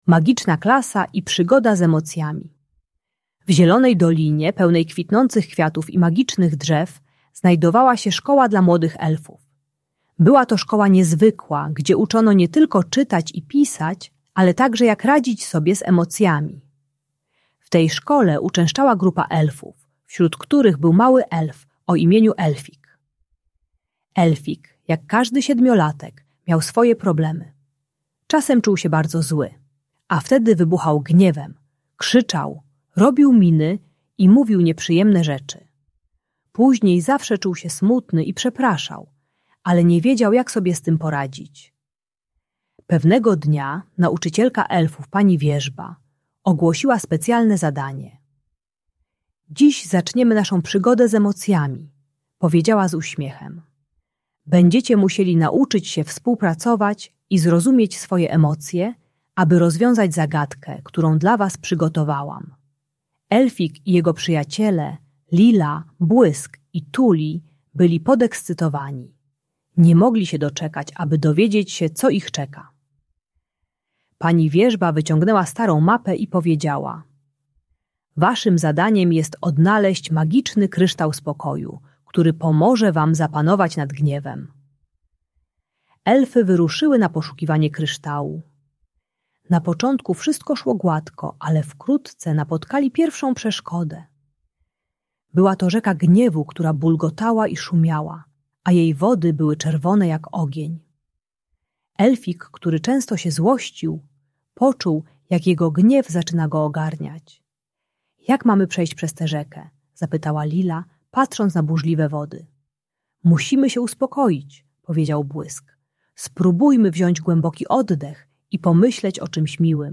Bajka dla dziecka które ma wybuchy złości i krzyczy, przeznaczona dla dzieci 6-8 lat. Ta audiobajka o złości i agresji uczy techniki głębokiego oddychania i myślenia o czymś przyjemnym, gdy narasta gniew.